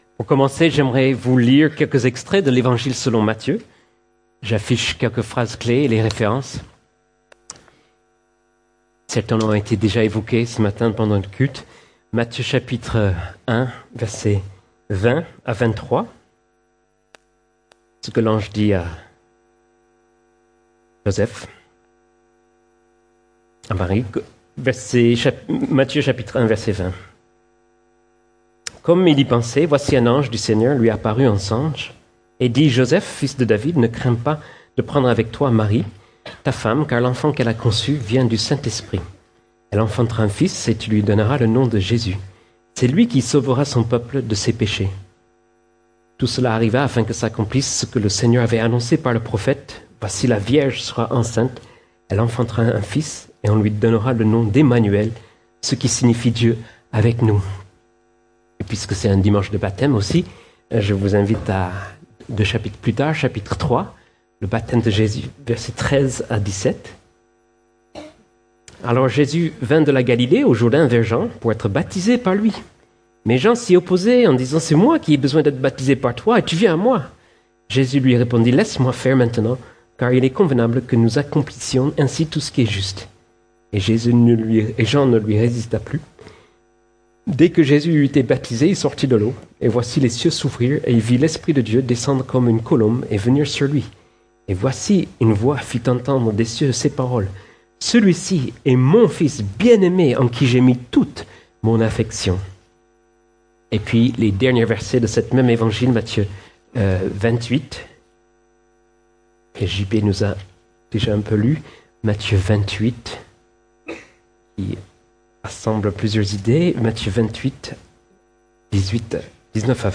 Sermons - Église Baptiste Toulouse Métropole